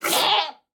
Minecraft Version Minecraft Version 1.21.5 Latest Release | Latest Snapshot 1.21.5 / assets / minecraft / sounds / mob / goat / screaming_milk3.ogg Compare With Compare With Latest Release | Latest Snapshot
screaming_milk3.ogg